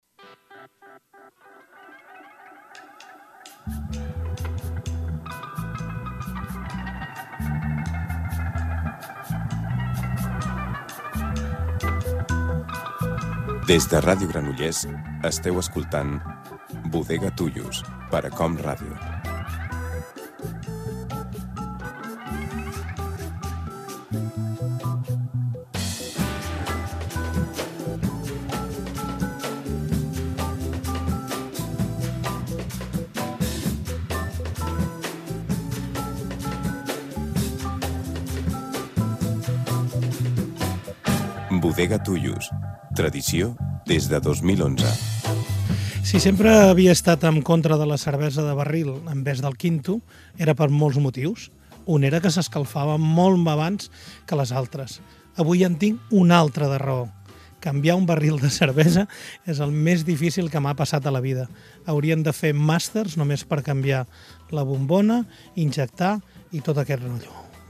Careta i presentació del programa
Musical